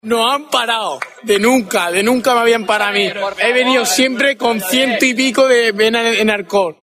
Los tíos del Eclipse, famosos por uno de los vídeos de Callejeros, diciendo una gran frase.